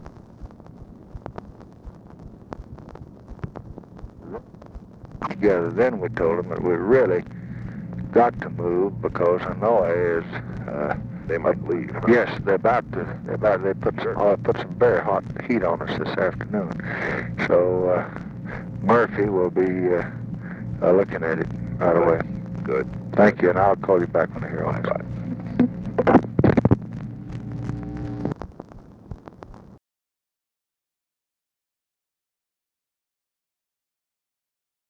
Conversation with RICHARD NIXON, November 22, 1968
Secret White House Tapes